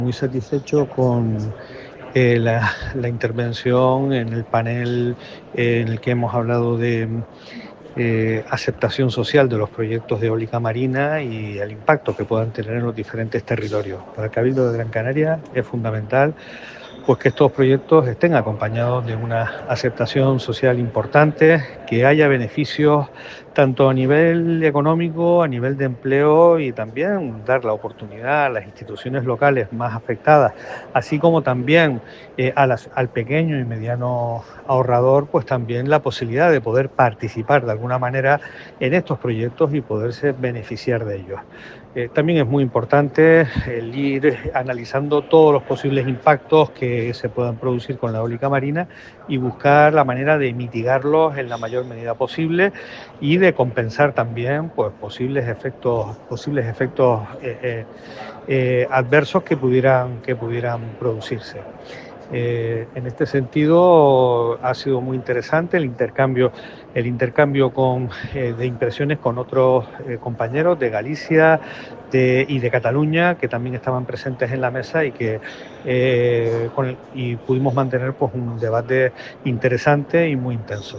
raul-garcia-brink-congreo-eolico-marino-cadiz.mp3